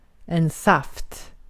Ääntäminen
IPA: /saft/